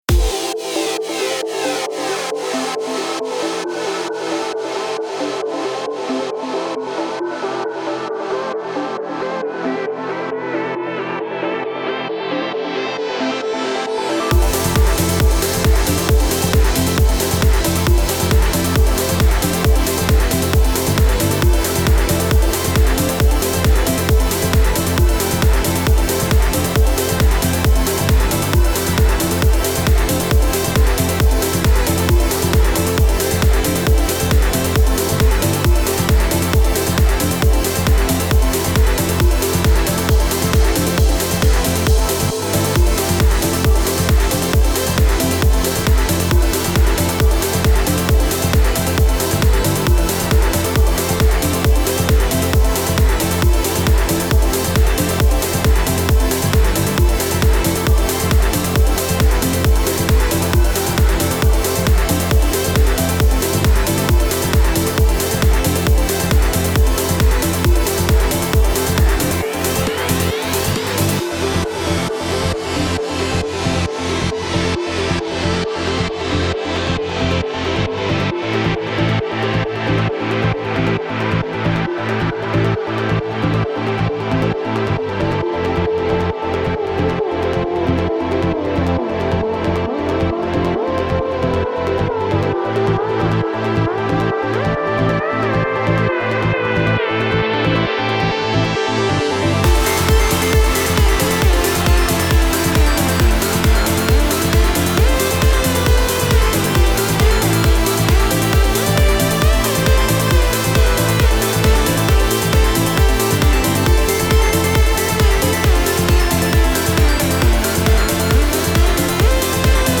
الکترونیک , امید‌بخش , پر‌انرژی , موسیقی بی کلام , هاوس